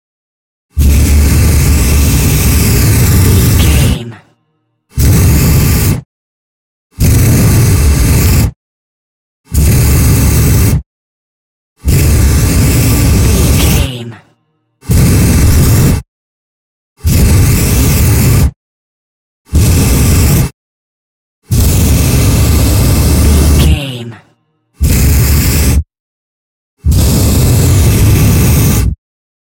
Flame thrower fire weapon
Sound Effects
industrial
mechanical